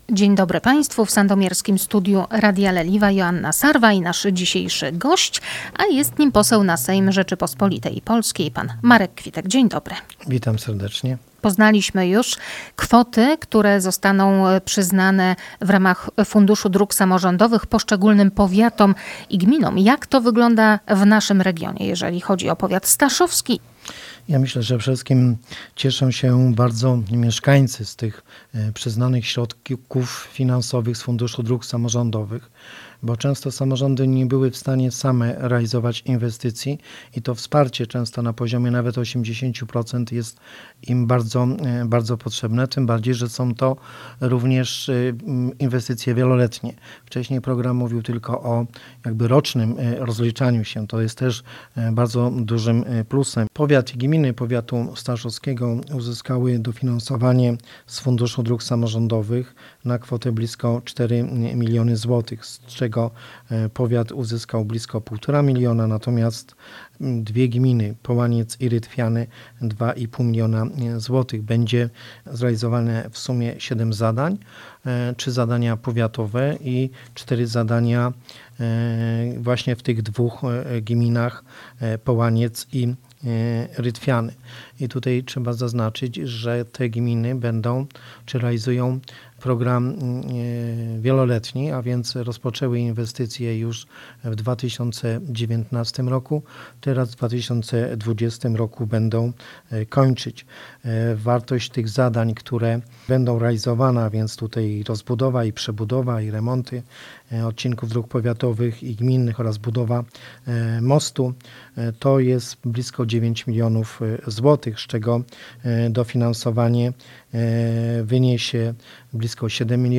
O szczegółach mówi poseł Marek Kwitek: